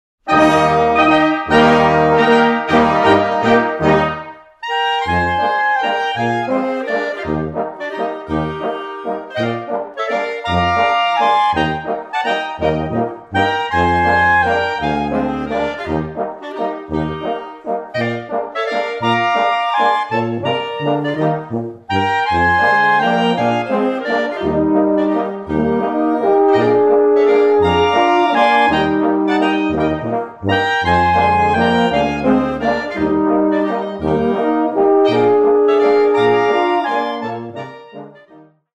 Ein wahrhaft bunter Reigen mit überlieferter und für den heutigen Gebrauch neugestalteter dörflicher Blasmusik!
"Häuserl im Wald" - Halbwalzer in Es aus den Handschriften der Kapelle Reiter, Haag 1850/1870
Doerfliche_Blasmusik_Haeuserl_im_21.mp3